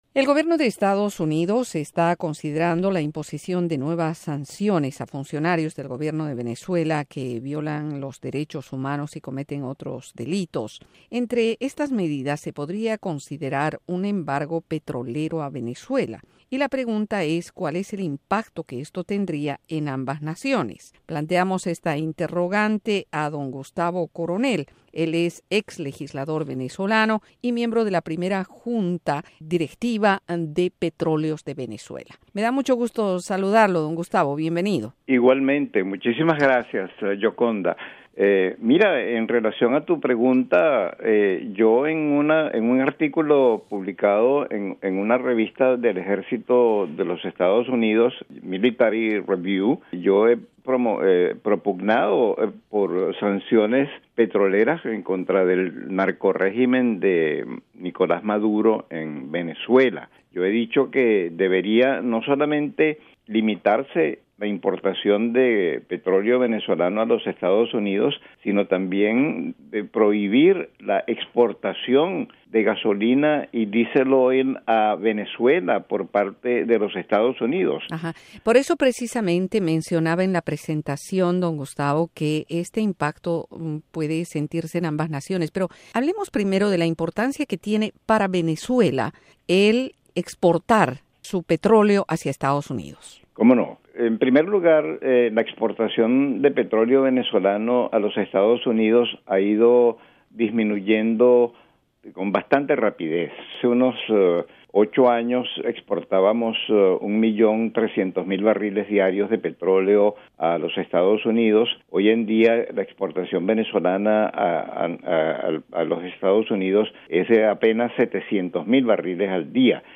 En esta entrevista este ex legislador venezolano pone el tema en contexto: